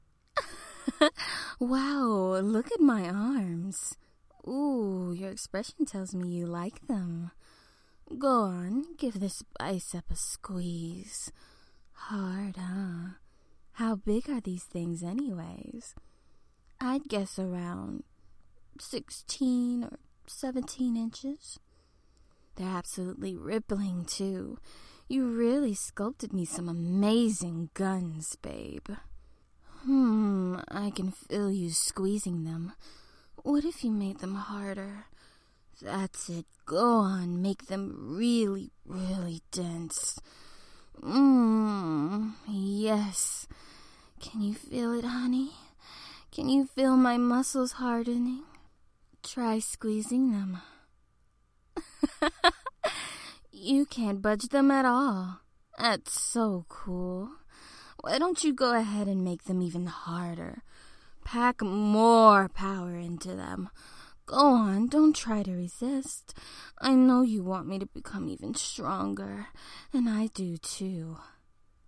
Muscle-Sample-No-Effects-No-Enhancement.mp3